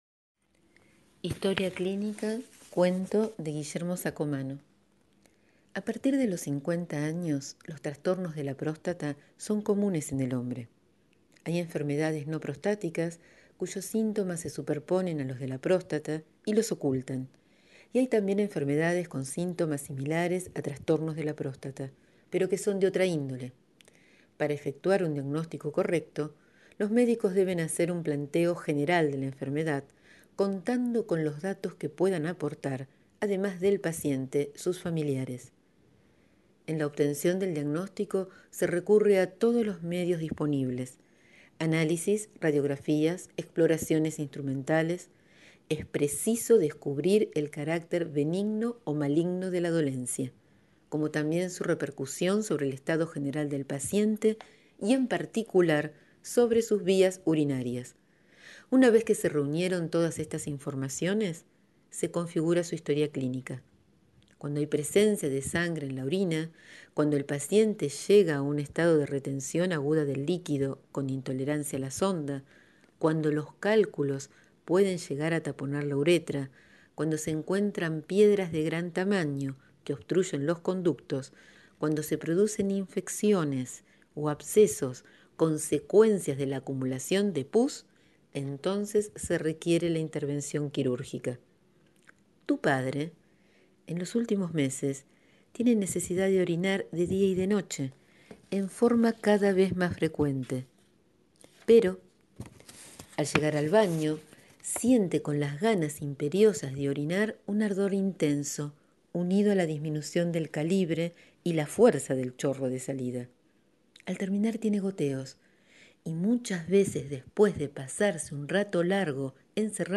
Hoy les leo «Historia clínica» un cuento de Guillermo Saccomanno (Argentino 1948).